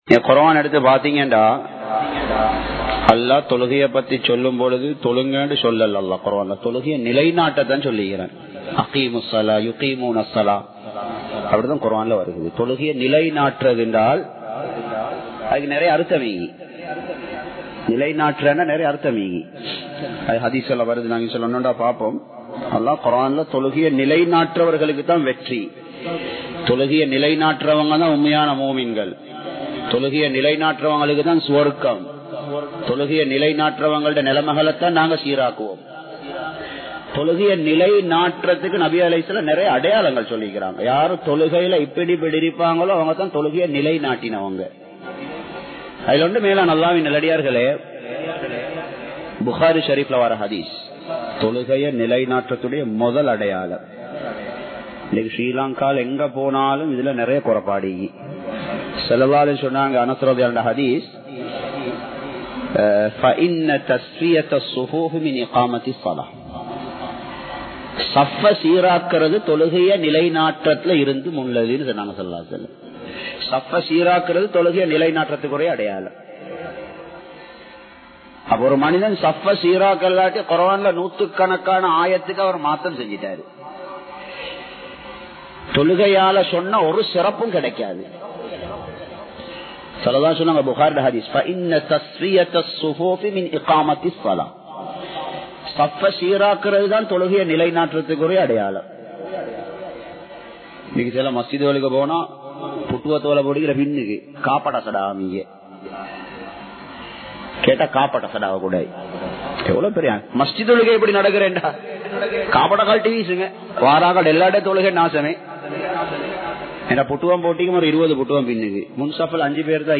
Colombo 06, Mayura Place, Muhiyadeen Jumua Masjith